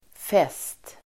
Uttal: [fes:t]